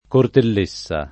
Cortellessa [ kortell %SS a ] cogn.